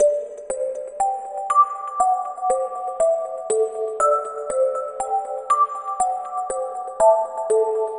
Night Chime 05.wav